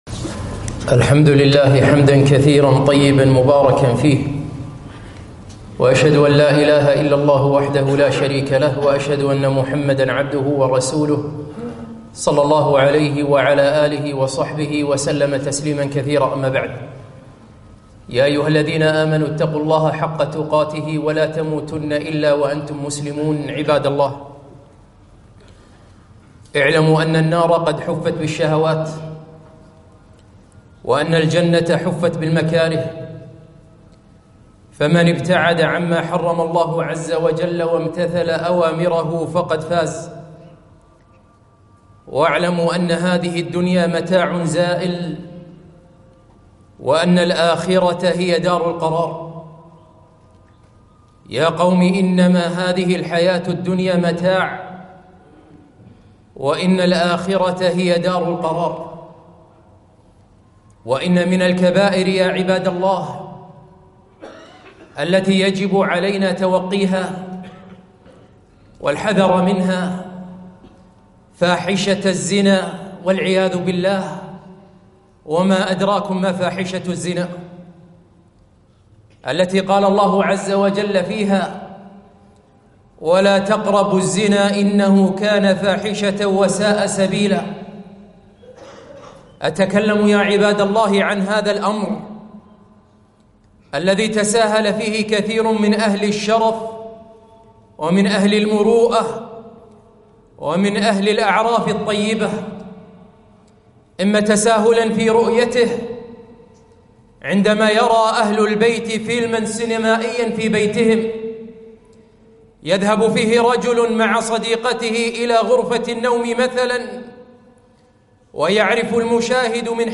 خطبة - دَقَّةٌ بِدَقَّة ولو زِدْتَ لِزَادَ السَّقَّا الزنا